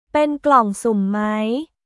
ペン グロンスーム マイ